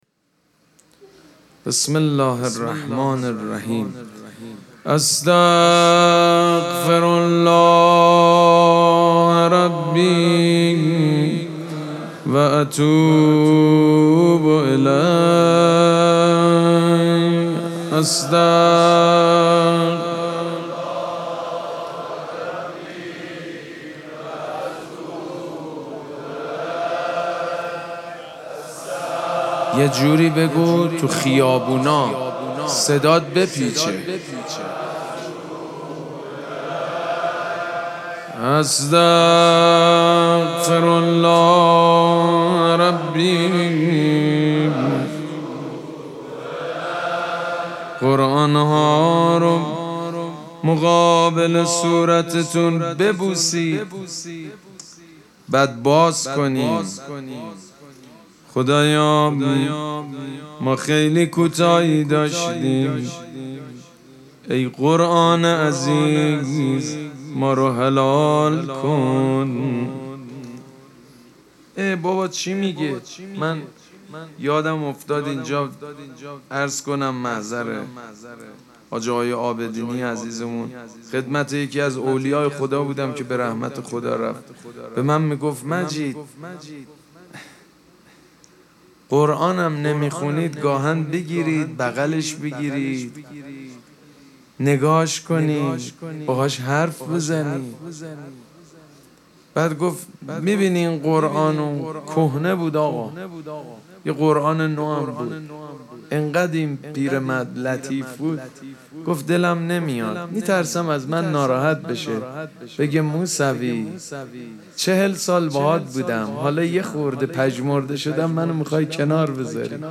مراسم مناجات شب بیست و سوم ماه مبارک رمضان یکشنبه ۳ فروردین ماه ۱۴۰۴ | ۲۲ رمضان ۱۴۴۶ حسینیه ریحانه الحسین سلام الله علیها